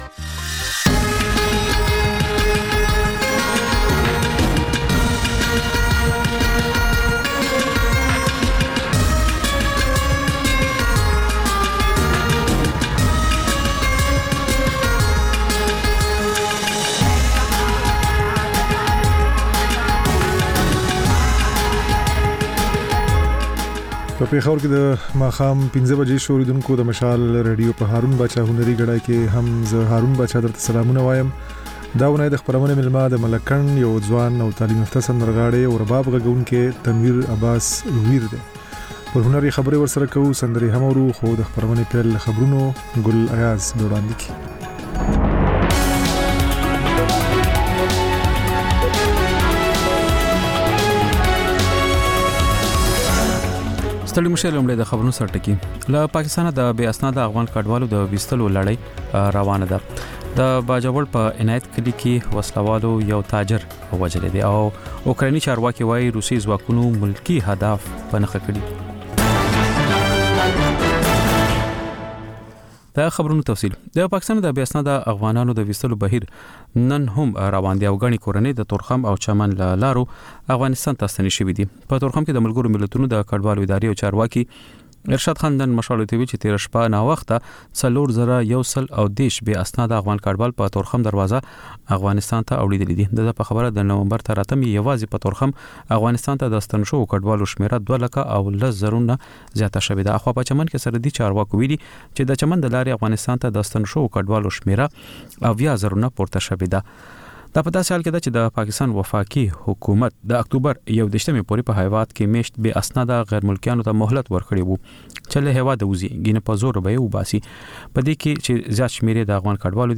د مشال راډیو ماښامنۍ خپرونه. د خپرونې پیل له خبرونو کېږي، بیا ورپسې رپورټونه خپرېږي. ورسره یوه اوونیزه خپرونه درخپروو. ځېنې ورځې دا ماښامنۍ خپرونه مو یوې ژوندۍ اوونیزې خپرونې ته ځانګړې کړې وي چې تر خبرونو سمدستي وروسته خپرېږي.